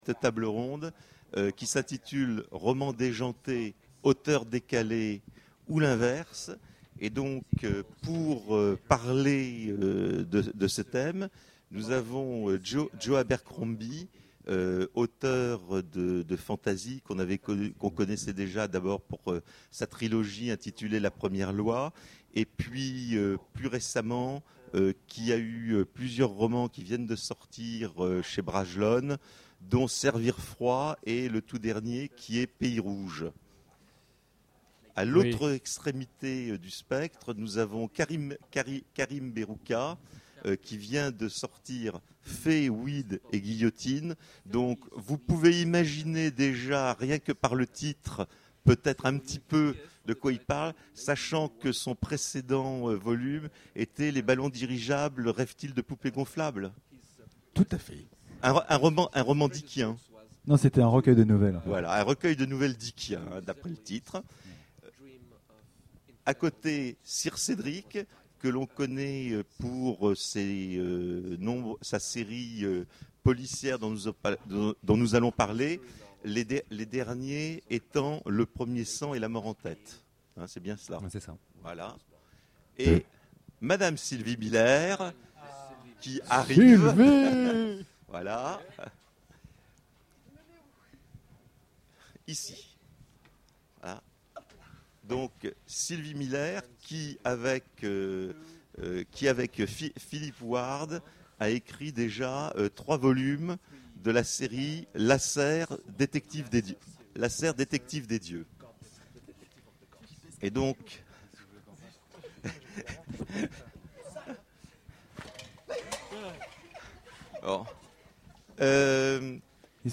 Imaginales 2014 : Conférence Romans déjantés, auteurs décalés